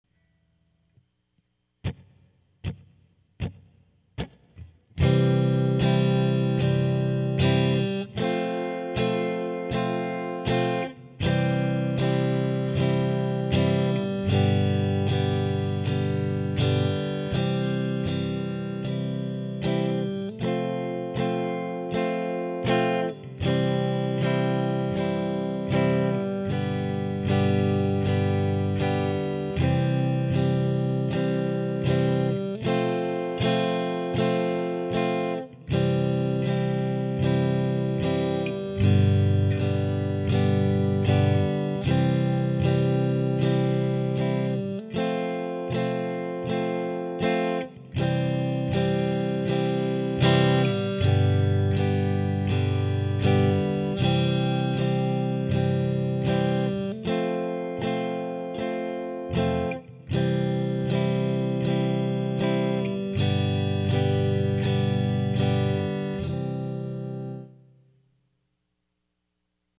Book 1: Low E String Exercise
Each letter is equal to four beats
C-F-C-G G-C-G-D D-G-D-A A-D-A-E E-A-E-B B-E-B-F#